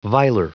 Prononciation du mot viler en anglais (fichier audio)
Prononciation du mot : viler